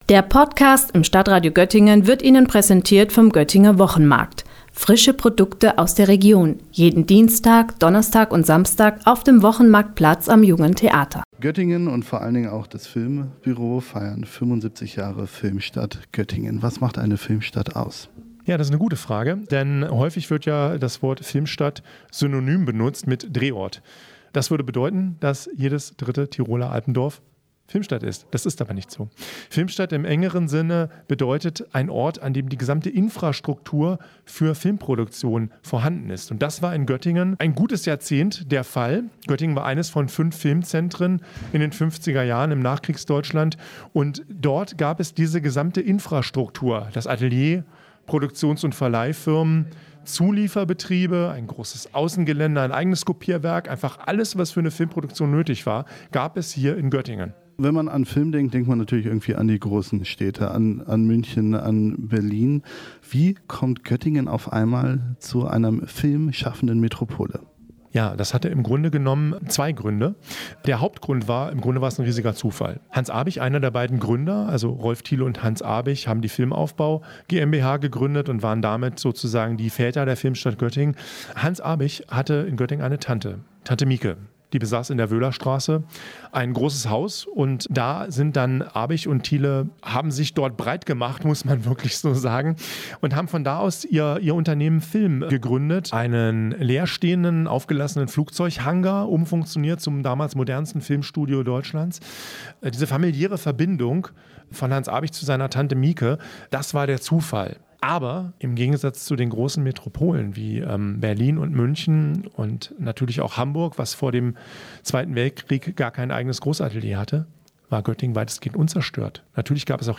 hat mit ihm über das „Hollywood an der Leine“ und das Festivalprogramm gesprochen.